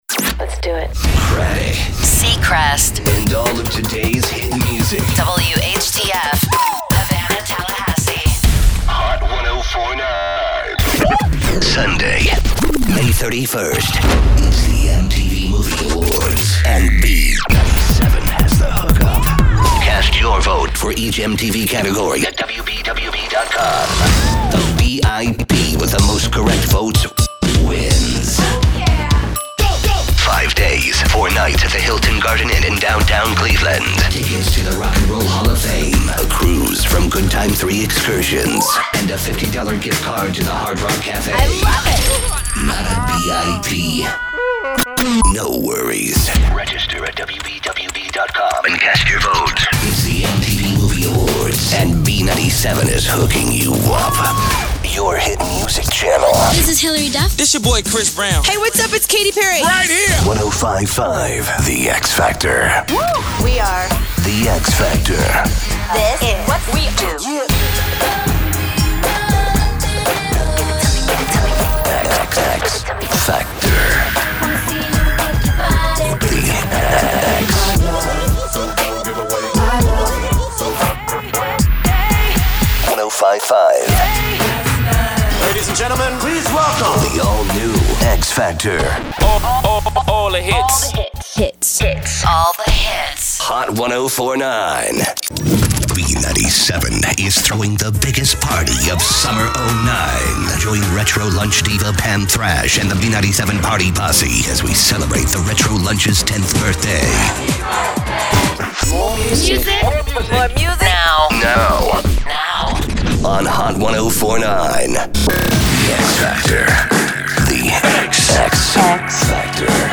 Format: CHR